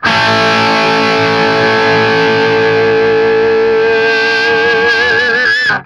TRIAD A   -L.wav